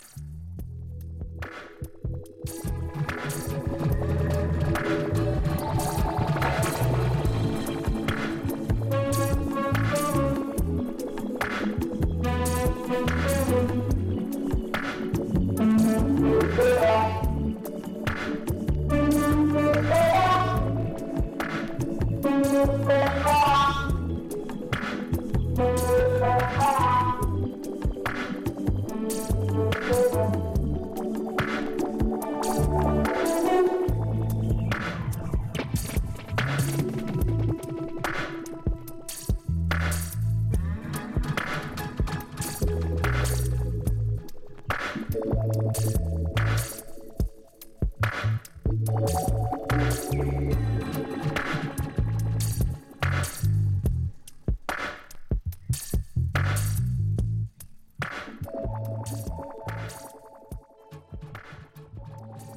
レイド・バッキン・メロウ・ラヴァーズ。
ダブ強烈！